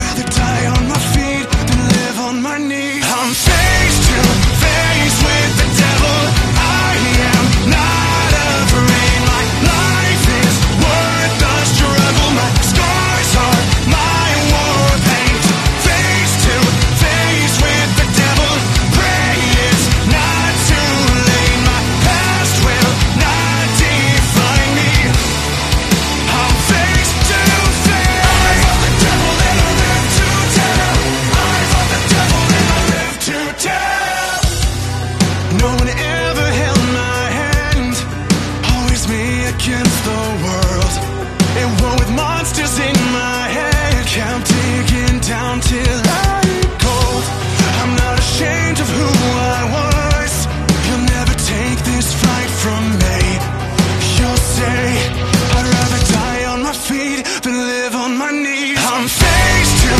Optimus Prime & Megatron Fighting Sound Effects Free Download